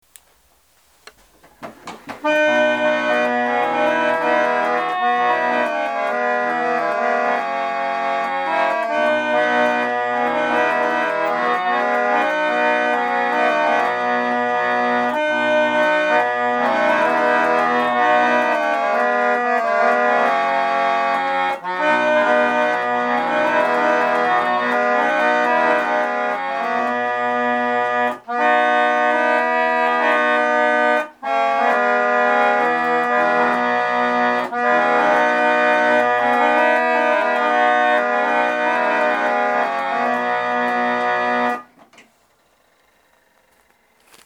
So what does it sound like?
The rich full sound is produced as air is moved over the brass reed, causing it to vibrate.
Interestingly, as the pallet lever slides from pin to pin during a held note, the pallet wavers, producing a vibrato effect.